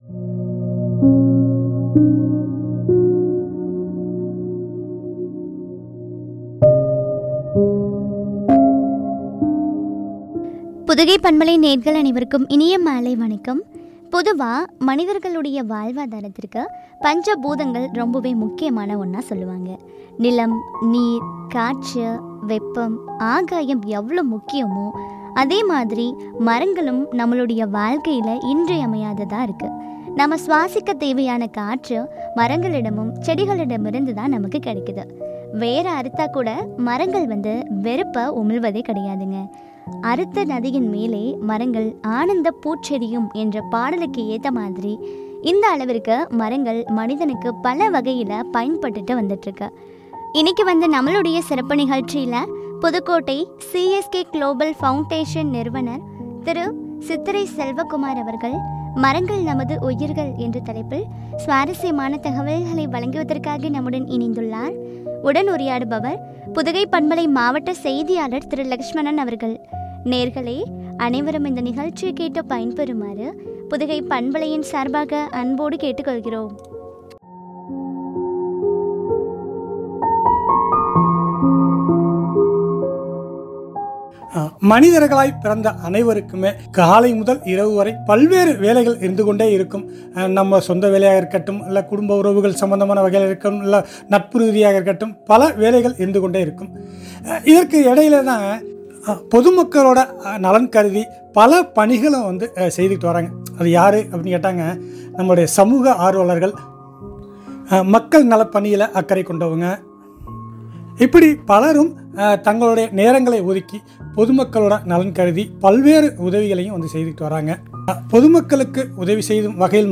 ” மரங்கள் நமது உயிர்கள்” குறித்து வழங்கிய உரையாடல்.